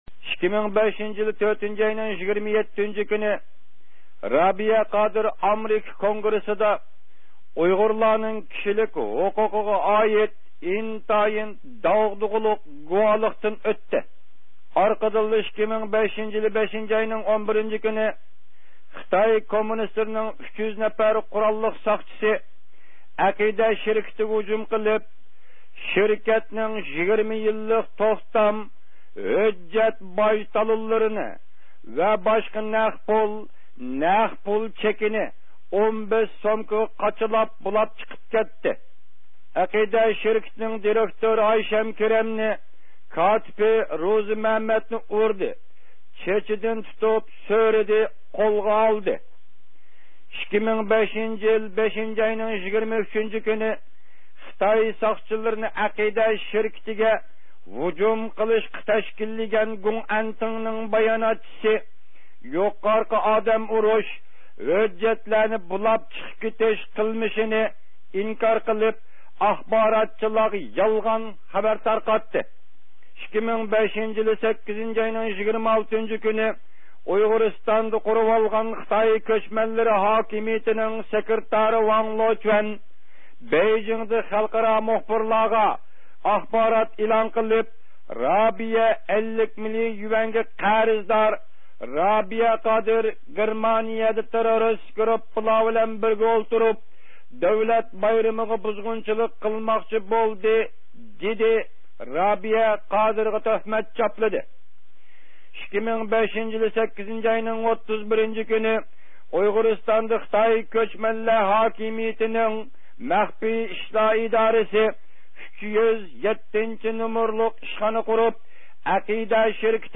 ئوبزورچىمىز